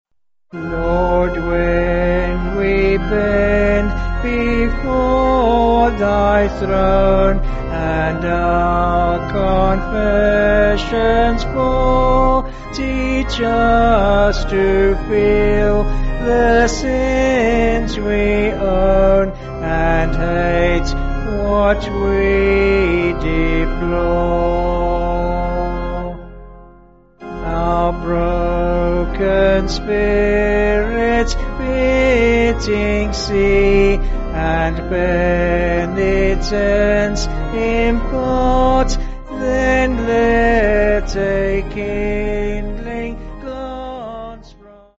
(BH)   4/Fm
Vocals and Organ